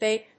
ビーピー‐ピー